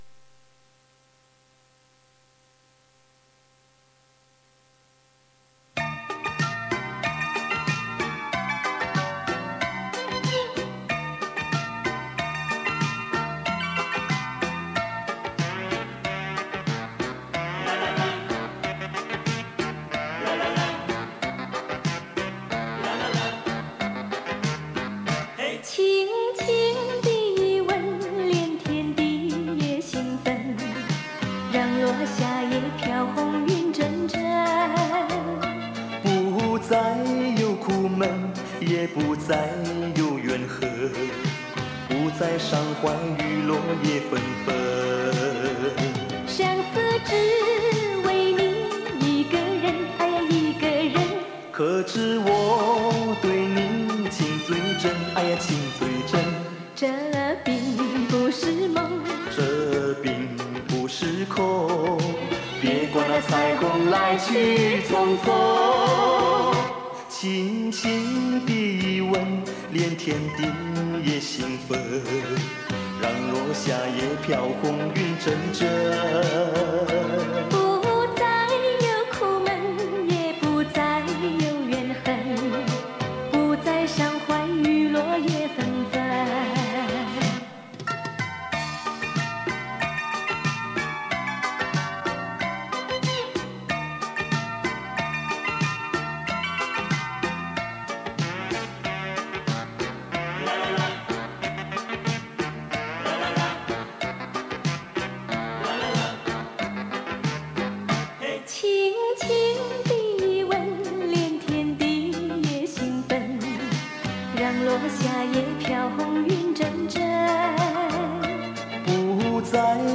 磁带数字化